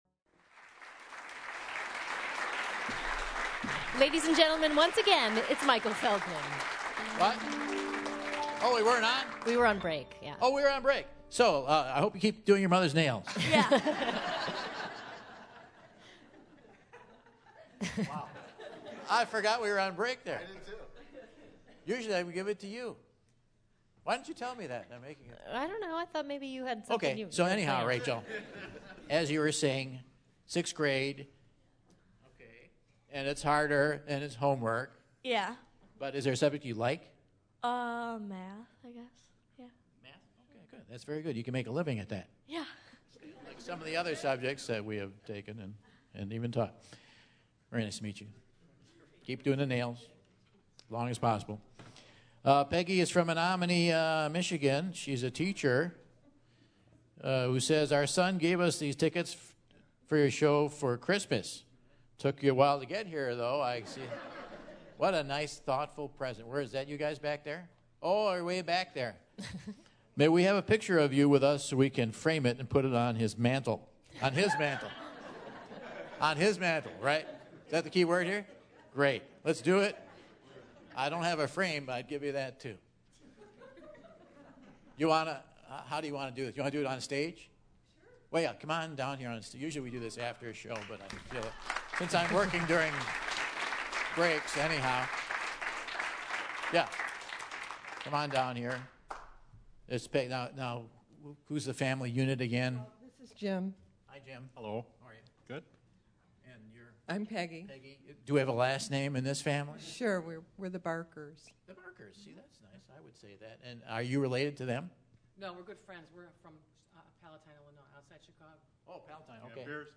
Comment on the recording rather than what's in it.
In the audience